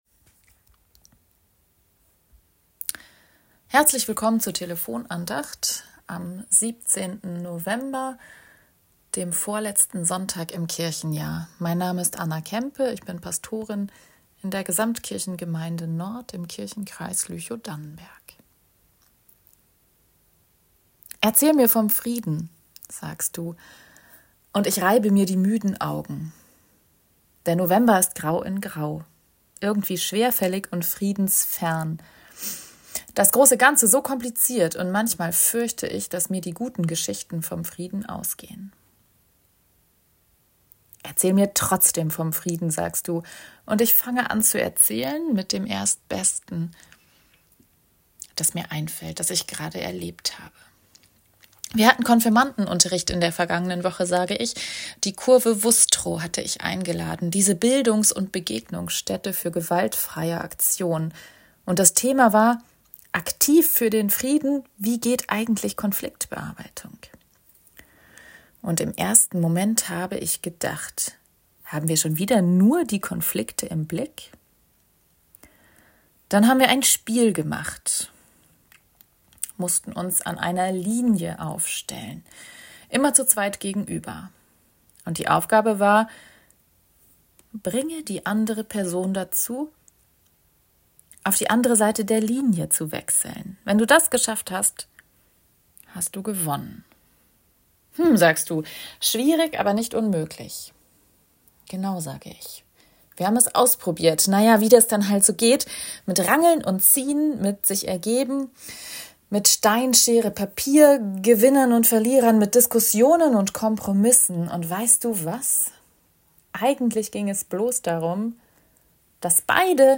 Telefon-Andacht vom 17.11.2024